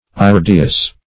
Search Result for " irideous" : The Collaborative International Dictionary of English v.0.48: Iridaceous \Ir`i*da"ceous\, Irideous \I*rid"e*ous\, a. [From NL.